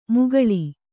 ♪ mugaḷi